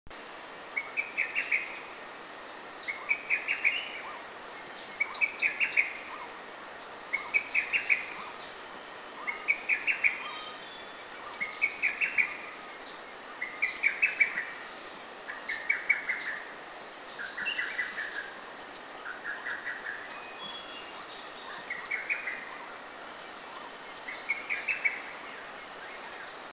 Lesser Cuckoo
Cuculus poliocephalus
LesserCuckoo.mp3